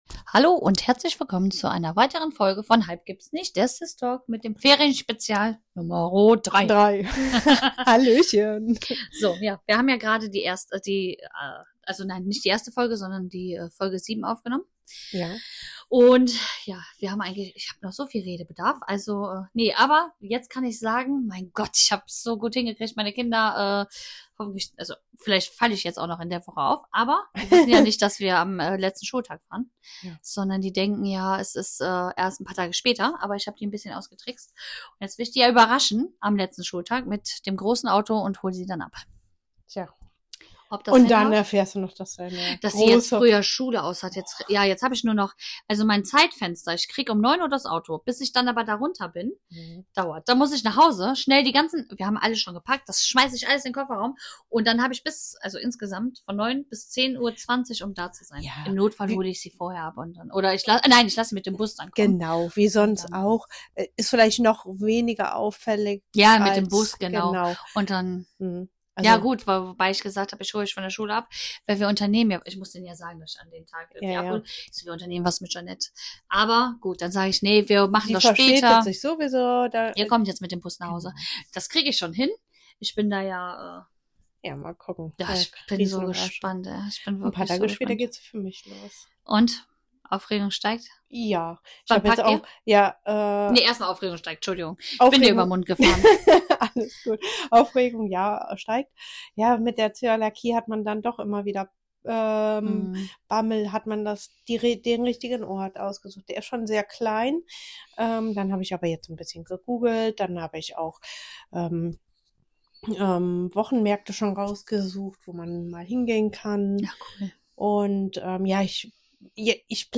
Die zwei Schwestern sprechen über Planungspanik, Reisesehnsucht und darüber, warum das Buchen oft stressiger ist als die Reise selbst.
Wie immer ehrlich, witzig und mit einer Portion Fernweh.